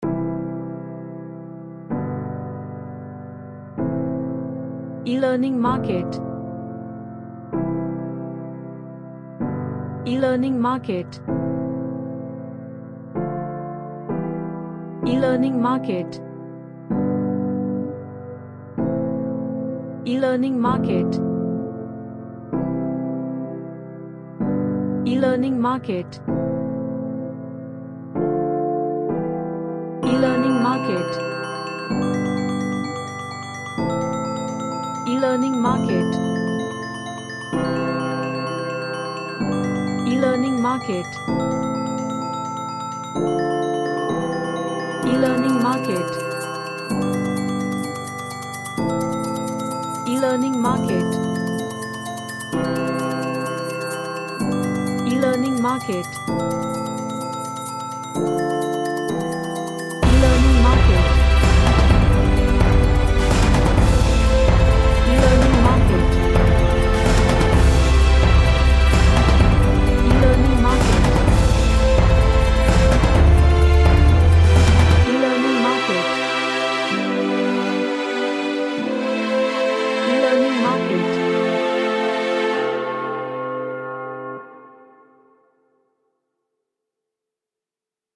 A happy track with groovy Orchestral Drums
Happy / Cheerful